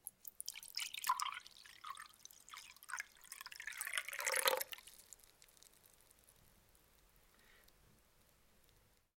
Pouring Drink
描述：Liquid being poured into a cup
标签： poured ale pouring liquid drink homerecording
声道立体声